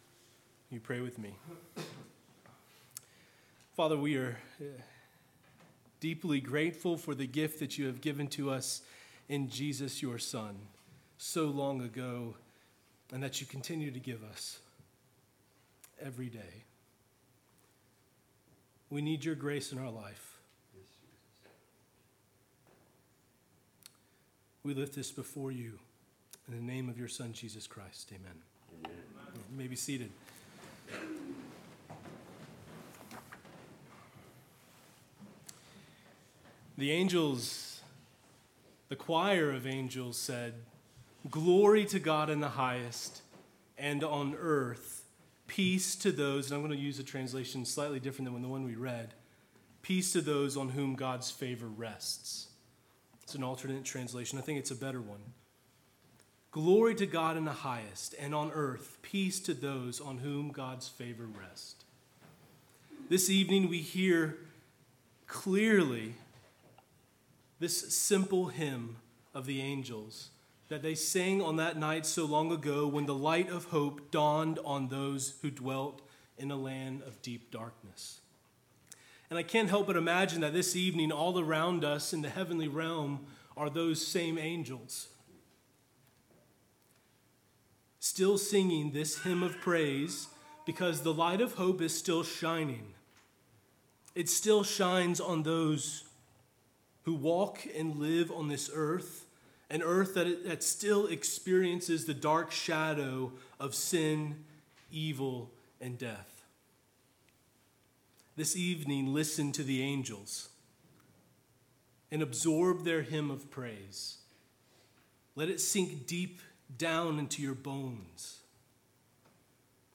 Early Christmas Eve Sermon